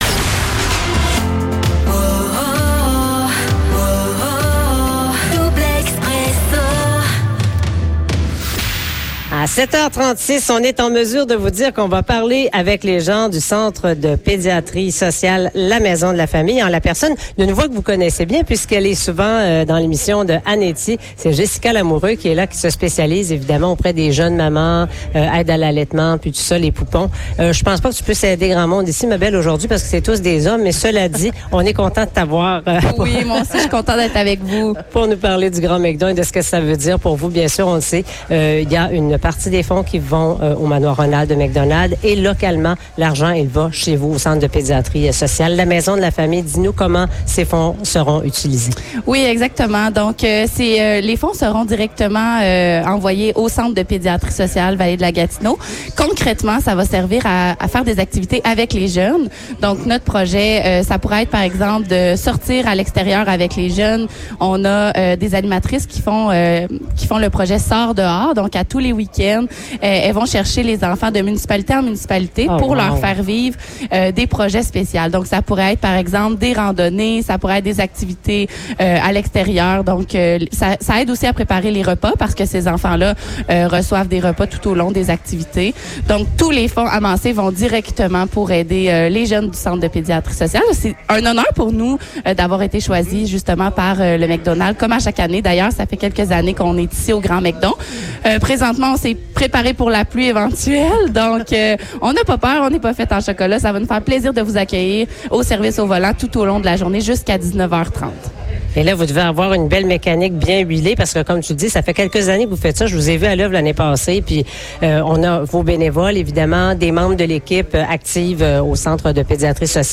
Entrevue
En direct du McDonald's de Maniwaki